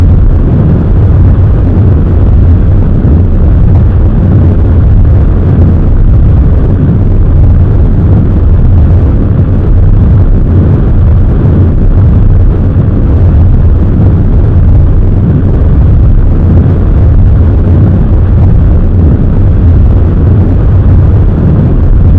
thrust3.mp3